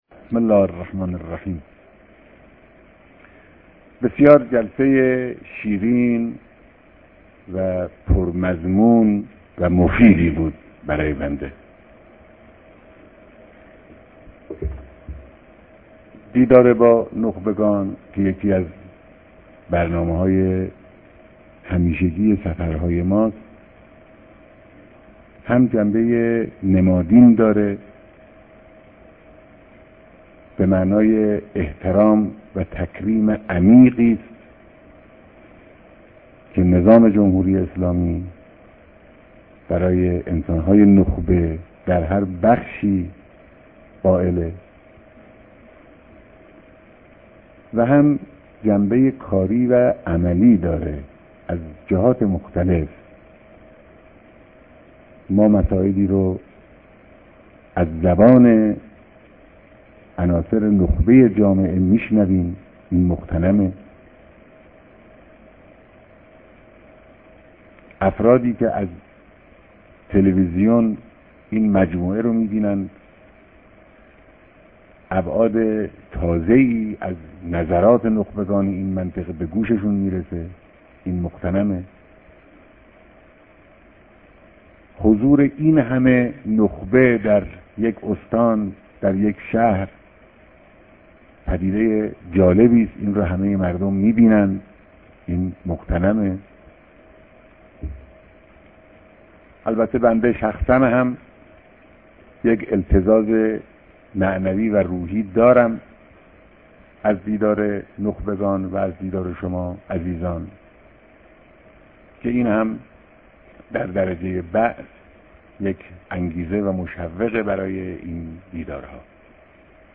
رهبر معظم انقلاب در ديدار با نخبگان و برگزيدگان كرمان